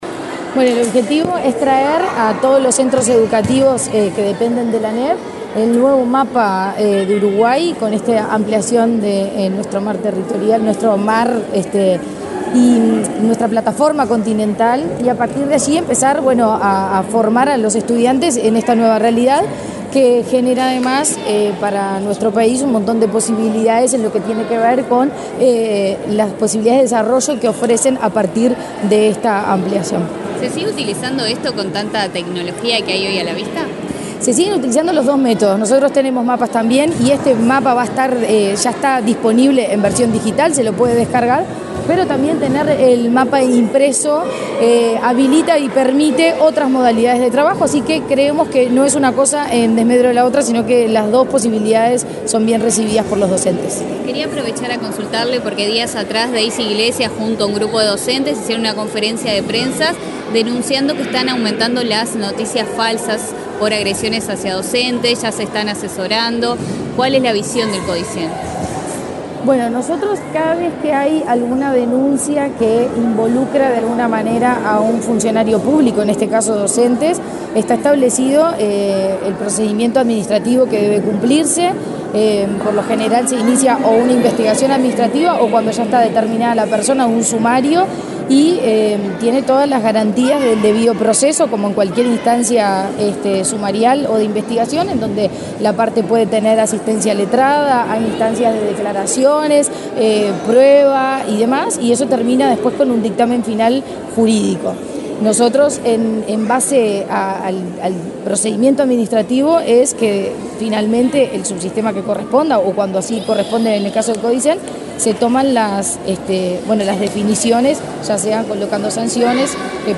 Declaraciones de la presidenta de ANEP, Virginia Cáceres
Este lunes 9 en la sede del Ministerio de Relaciones Exteriores, la presidenta de la Administración Nacional de Educación Pública (ANEP), Virginia Cáceres, dialogó con la prensa, luego de participar en la presentación de una lámina del Uruguay con sus jurisdicciones marítimas, realizada por el Servicio de Oceanografía, Hidrografía y Meteorología de la Armada Nacional.